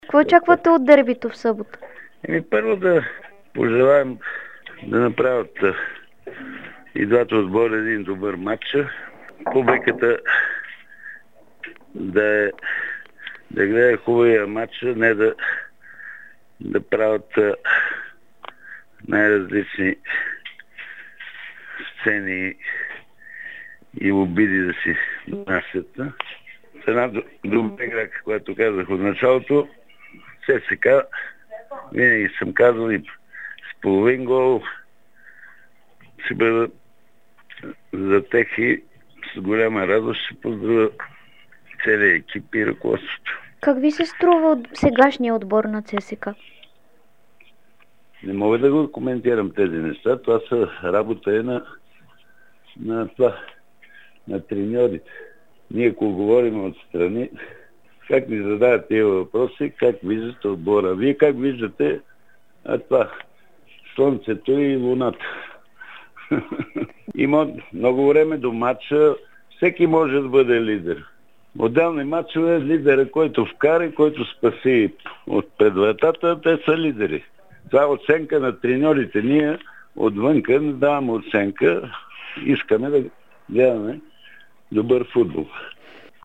Треньор номер 1 на България за XX век даде кратко интервю пред dsport и Дарик радио преди дербито между отборите на Левски и ЦСКА.